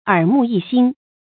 注音：ㄦˇ ㄇㄨˋ ㄧ ㄒㄧㄣ
耳目一新的讀法